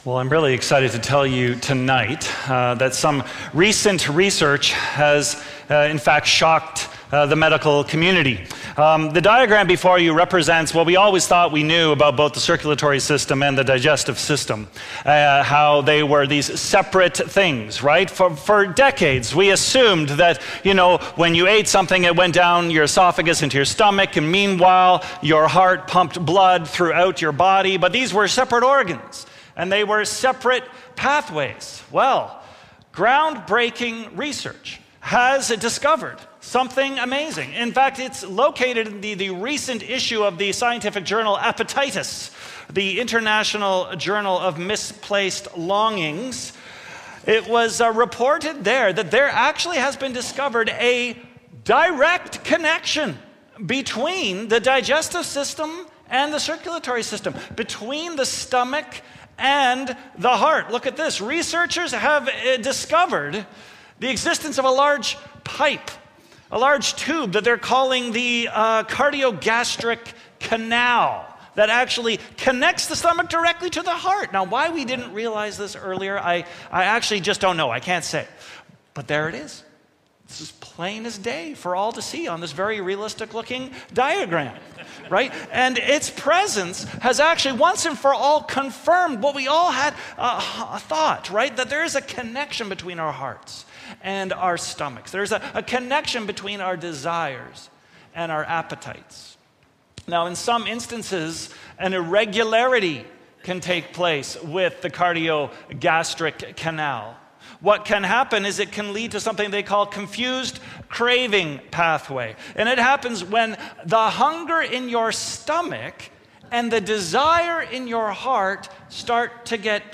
Trinity Streetsville - Grappling With Gluttony | The "S" Word | Trinity Sermons - Archive FM